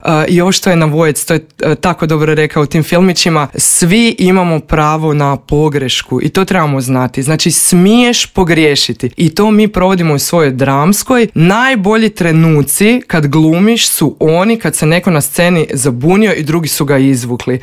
razgovarali smo u Intervjuu Media servisa.